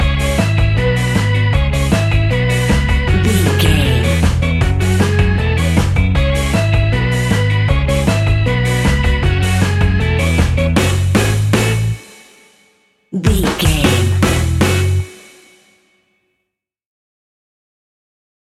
Classic reggae music with that skank bounce reggae feeling.
Aeolian/Minor
F#
reggae
laid back
chilled
off beat
drums
skank guitar
hammond organ
percussion
horns